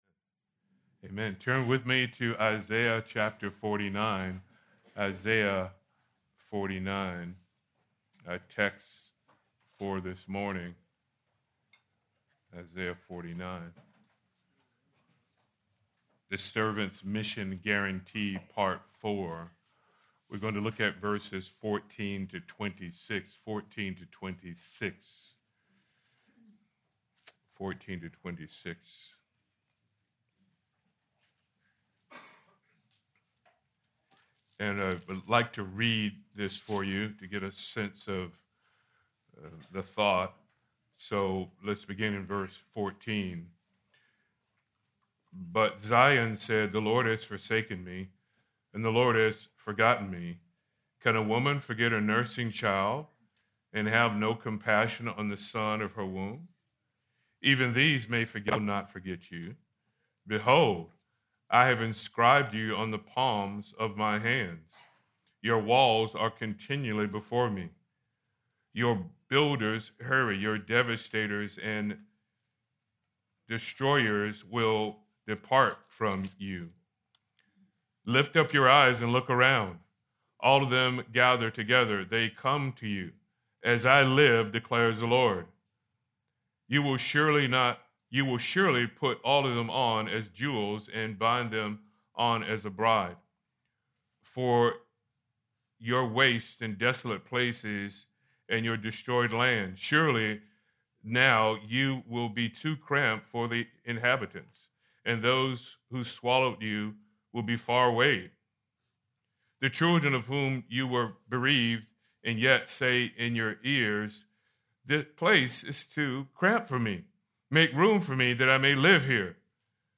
March 8, 2026 - Sermon | Anchored | Grace Community Church